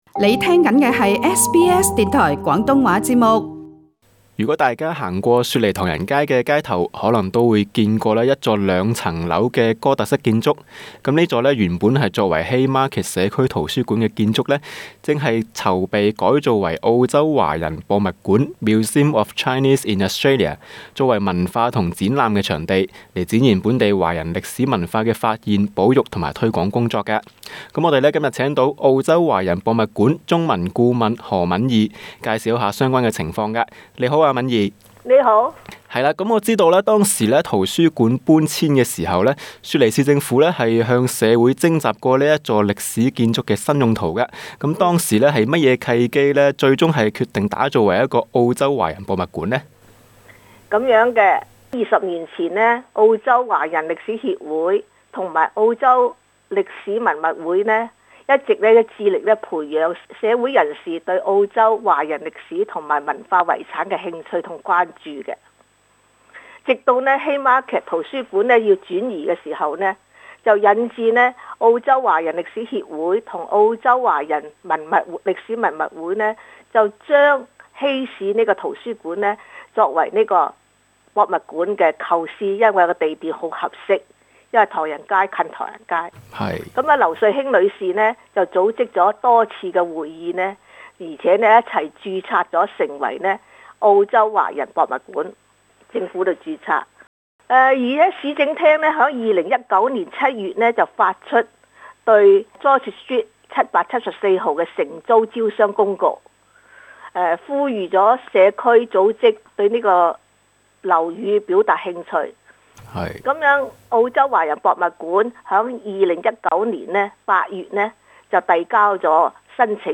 【社區訪問】